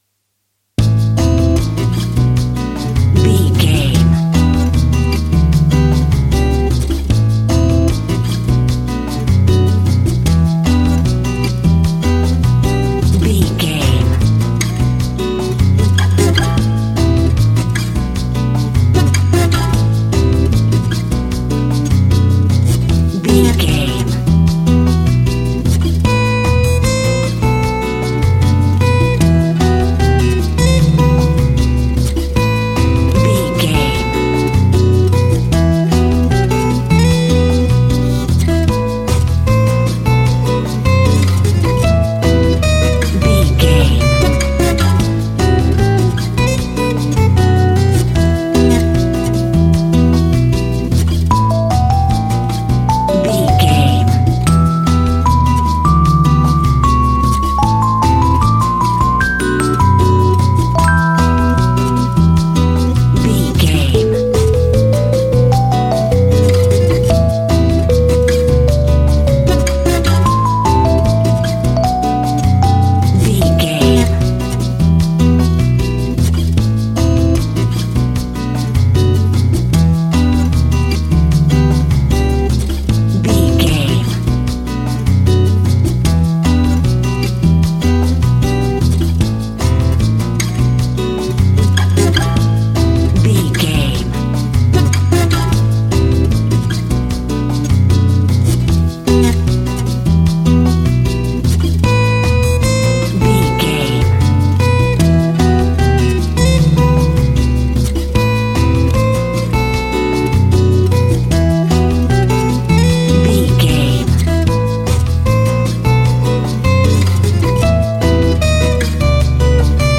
Uplifting
Aeolian/Minor
maracas
percussion spanish guitar